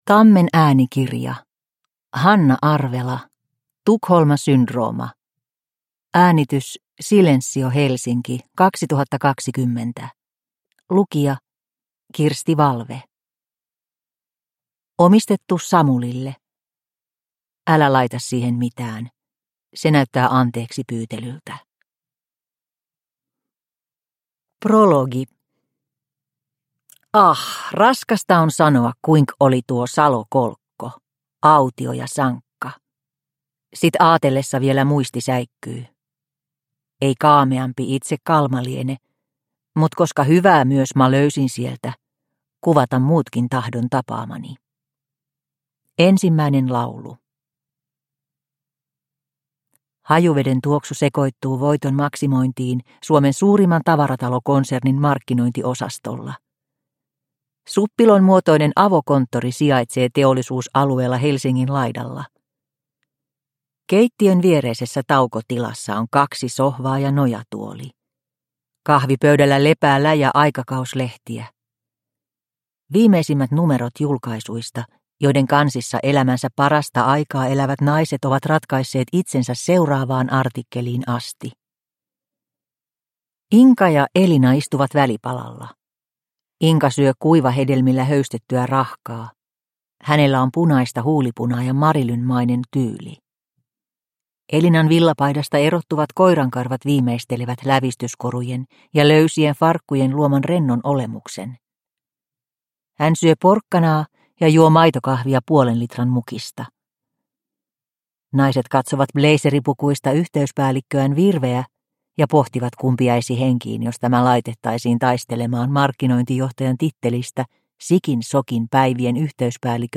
Tukholma-syndrooma (ljudbok) av Hanna Arvela